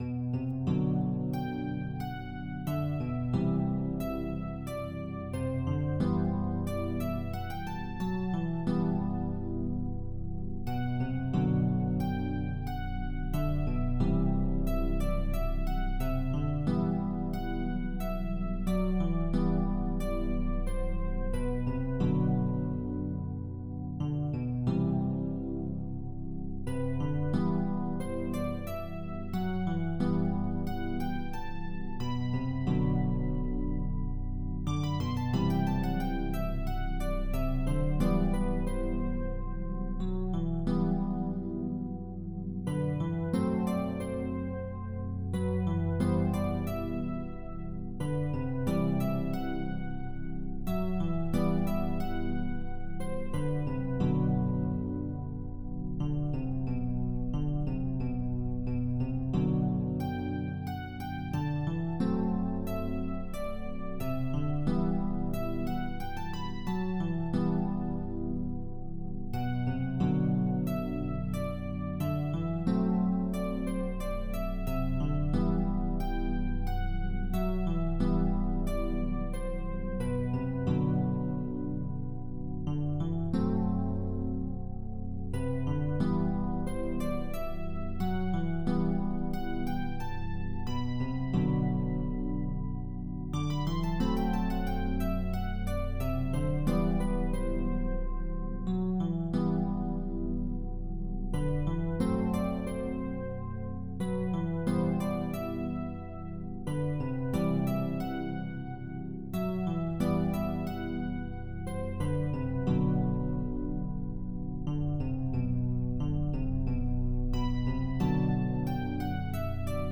Simple E-Piano and pad arrangement. B Phrygian 3 main verse chord progressions and one "chorus" progression. Calm and bitter but with some odd ambiguity. And very cold.